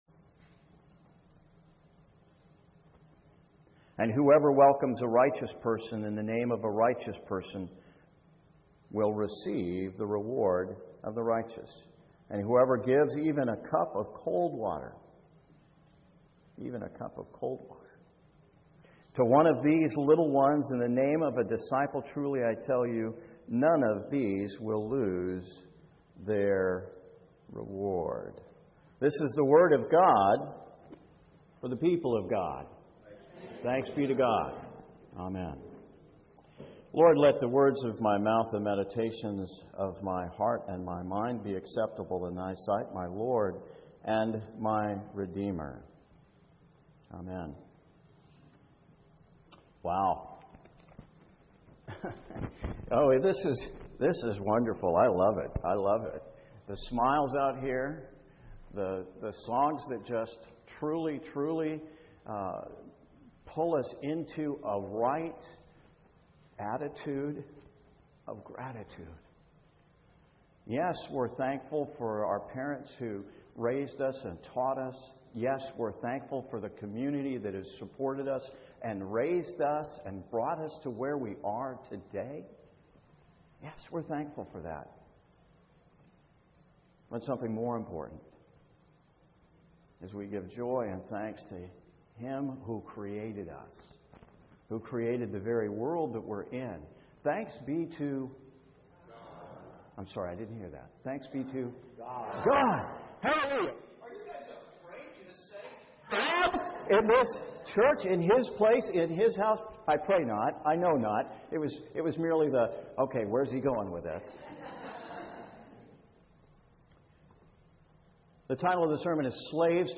Sermon 29 June 2014